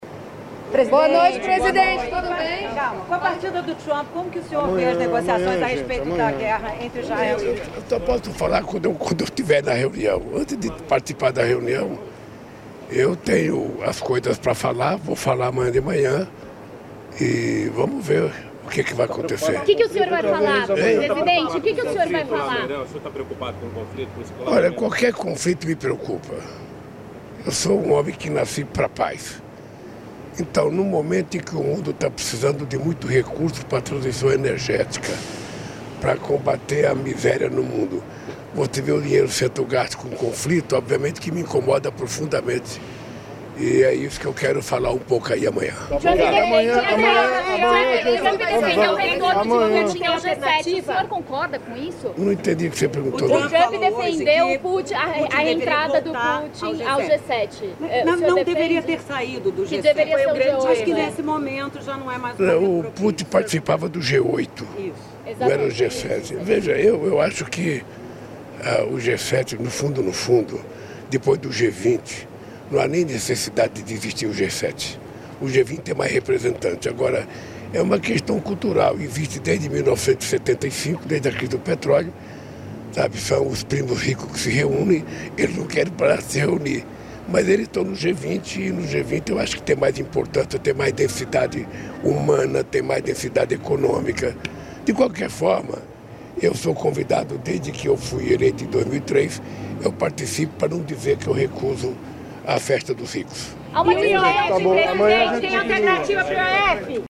Ouça a íntegra das falas do presidente Luiz Inácio Lula da Silva, do ministro da Justiça e Segurança Pública, Ricardo Lewandowski, e da ministra do Meio Ambiente e Mudança do Clima, Marina Silva, durante reunião para envio do Projeto de Lei que aumenta as sanções penais para crimes ambientais, nesta terça-feira (15), no Palácio do Planalto.